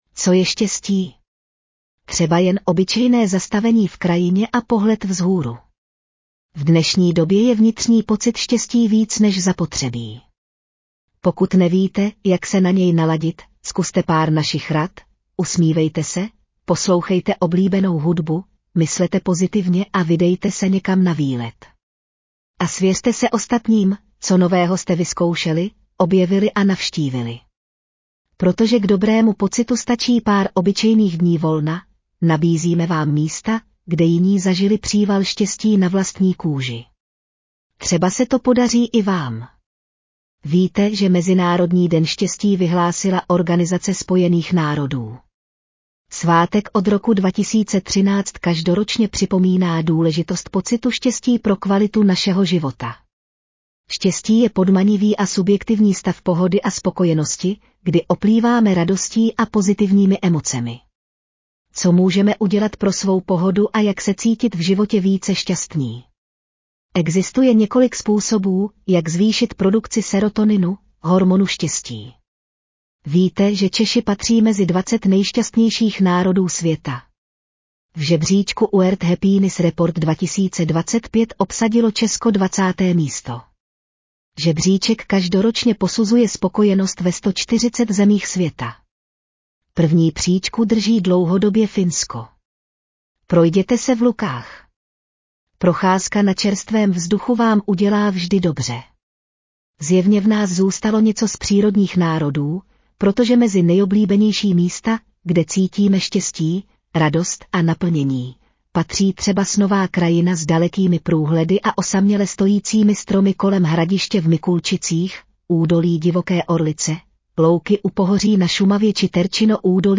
13964vlastaneural.mp3